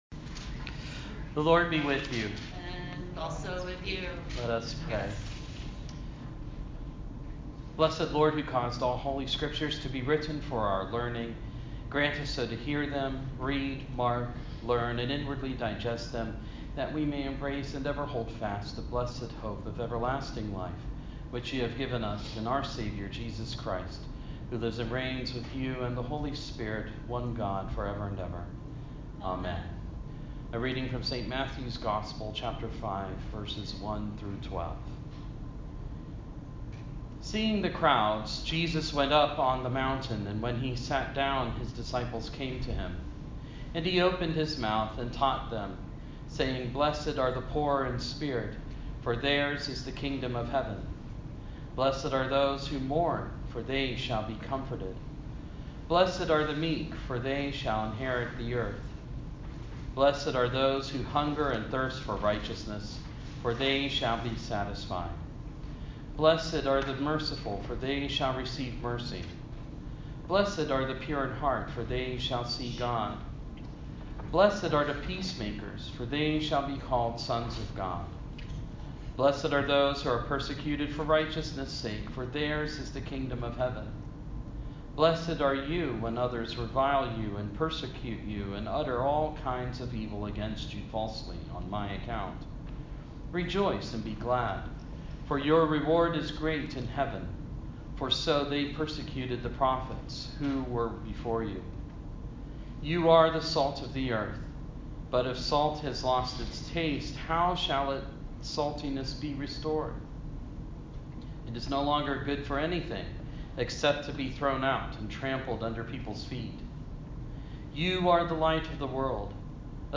sermon-on-the-mount-1.mp3